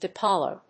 • : -əʊlə(ɹ)